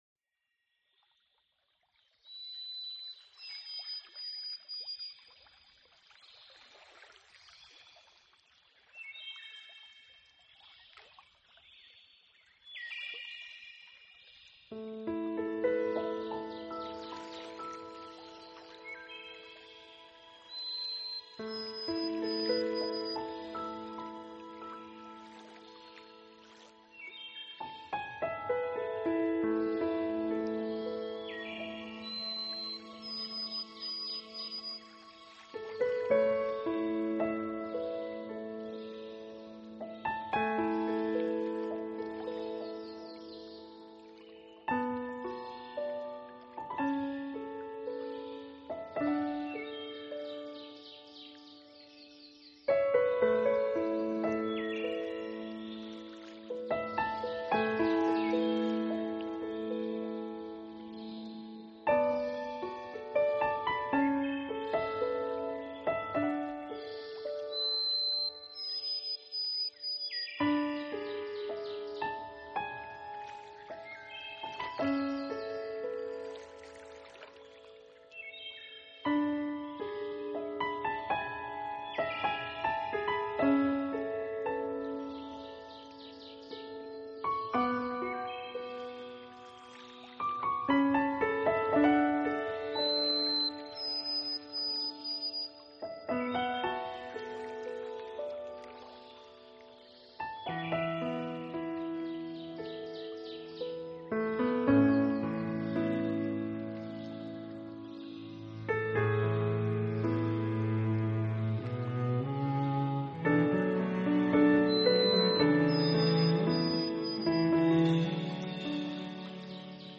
【新世纪纯音乐】
海湾作为主题背景，钢琴的水晶灵动，配乐温柔细腻。